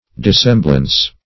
Dissemblance \Dis*sem"blance\, n. [Dissemble + -ance.]